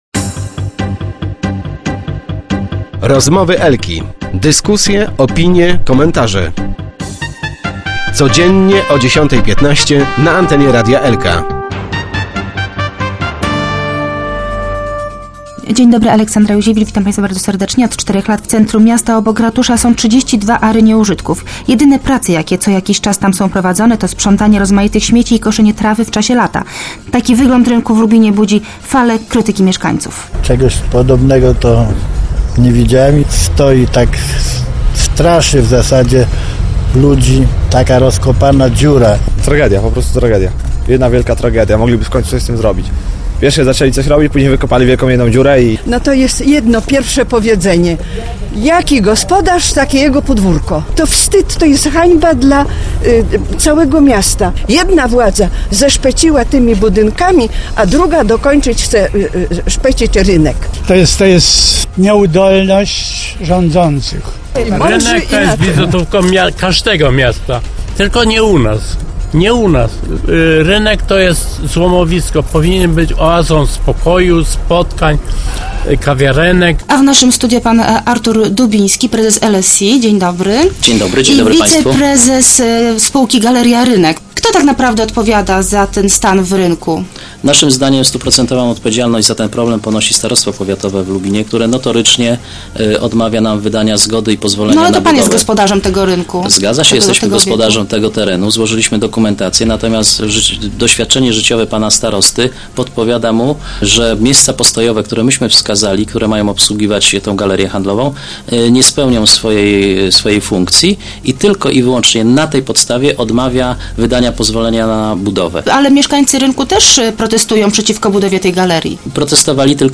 Start arrow Rozmowy Elki arrow Spór o rynek